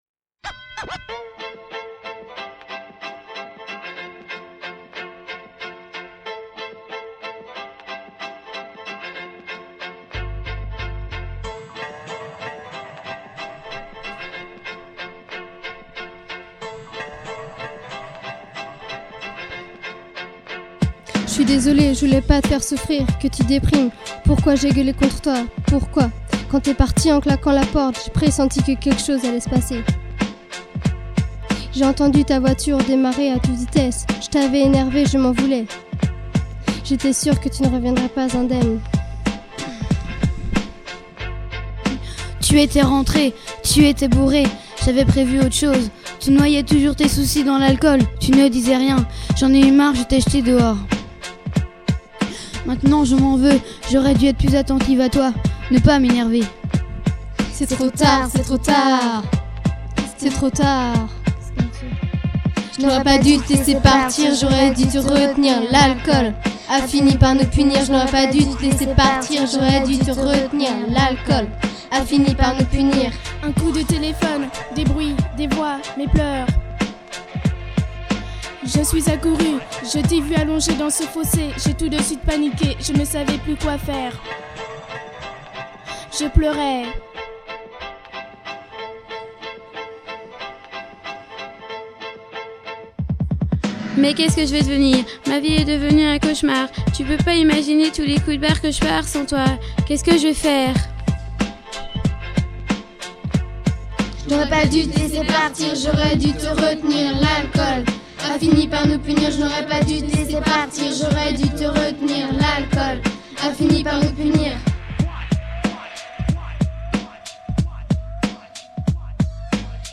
Le stage rap ragga
En juillet 2003, l'association des Zetlaskars a aidé une dizaine de jeunes à concevoir des chansons de rap.